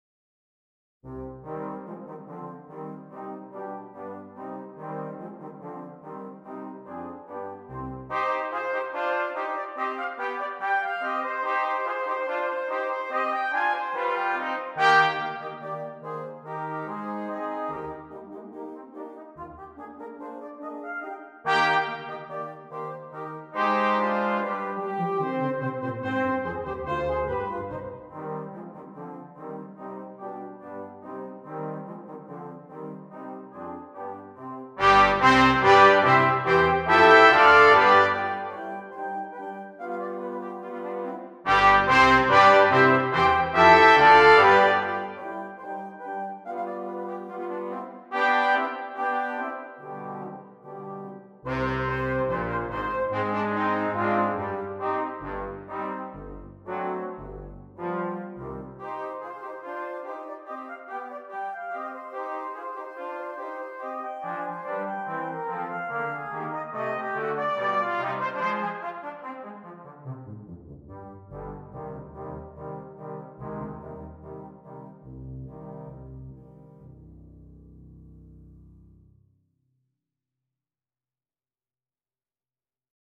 • Ten Piece Brass Ensemble